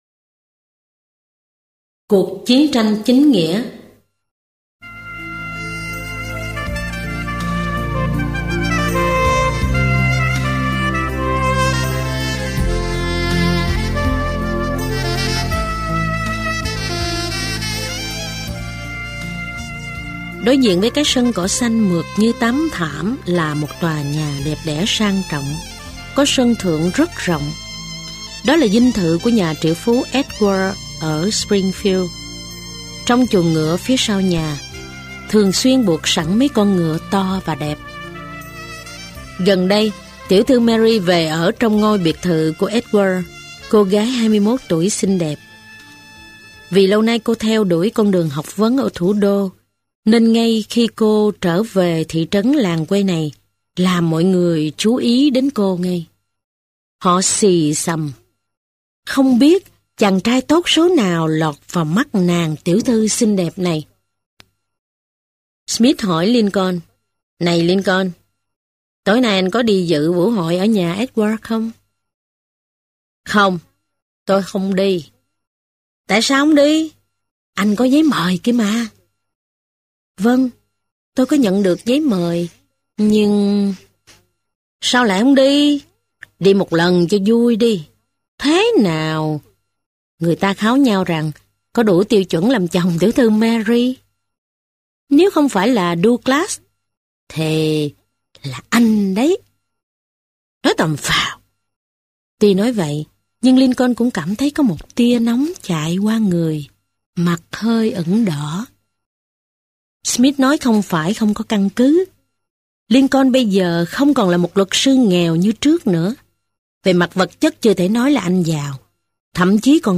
Sách nói Abraham Lincoln - Trần Thu Phàm - Sách Nói Online Hay